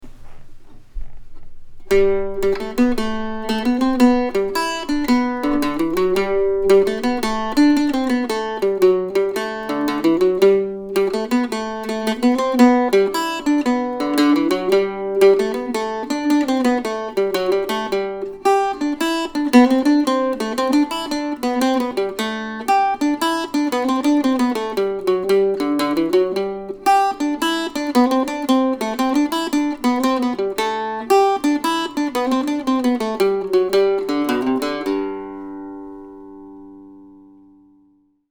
Autumn Suite, November, 2020 (for Octave Mandolin or Mandocello)